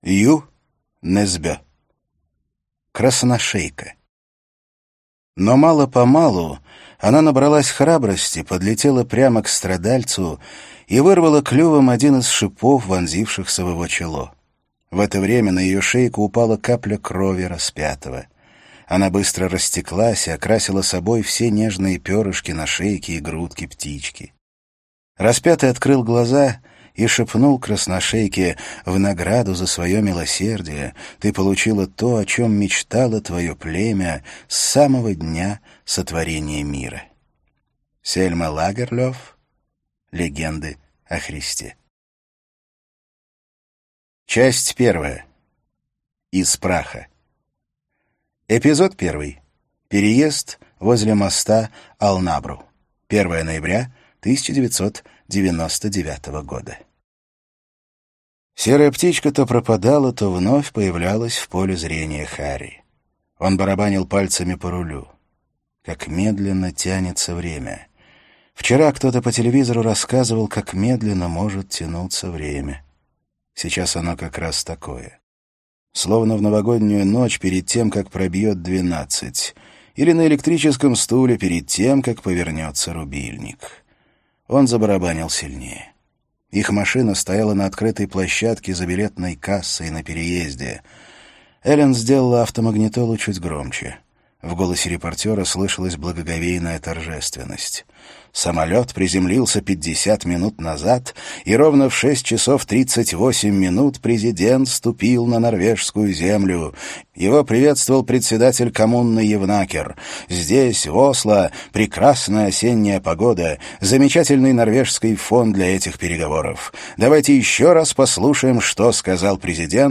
Аудиокнига Красношейка - купить, скачать и слушать онлайн | КнигоПоиск